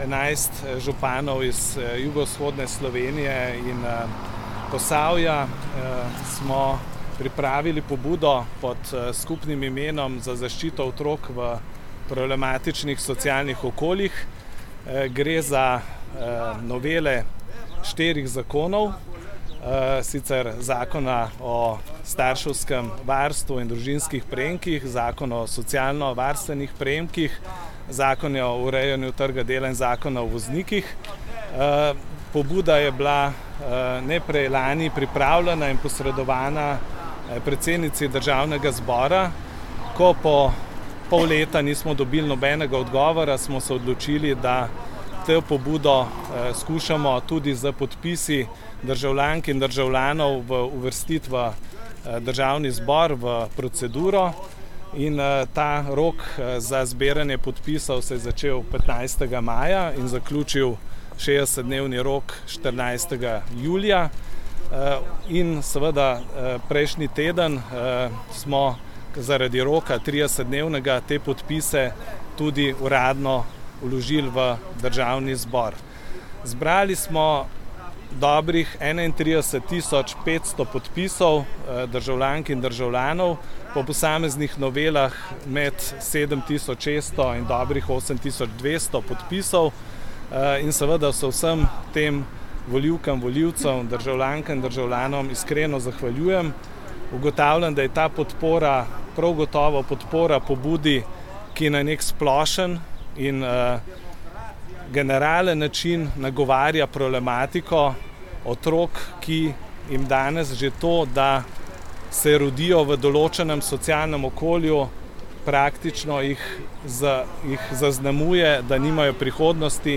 Novomeški župan Gregor Macedoni danes v Ljubljani o pobudi